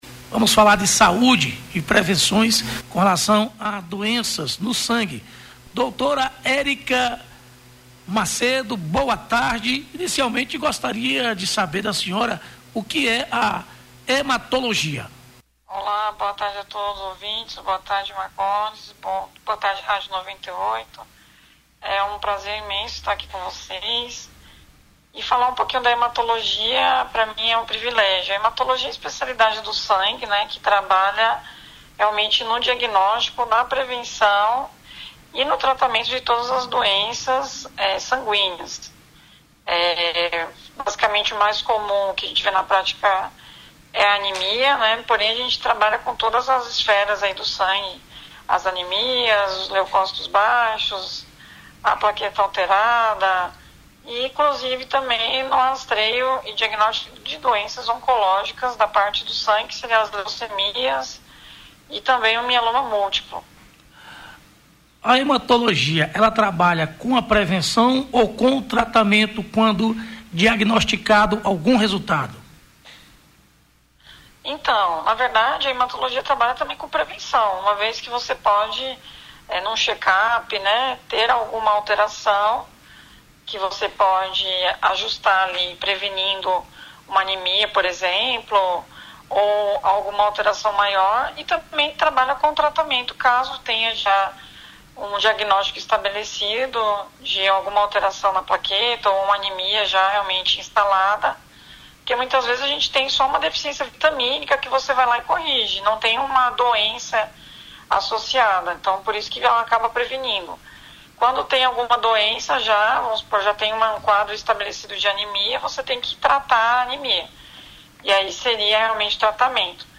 hematologista, falando da medicina integrativa